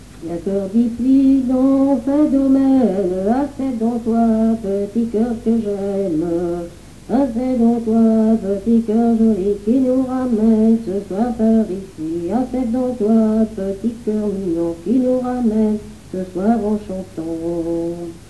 Mémoires et Patrimoines vivants - RaddO est une base de données d'archives iconographiques et sonores.
circonstance : fiançaille, noce ; circonstance : compagnonnage ;
Genre énumérative
Catégorie Pièce musicale inédite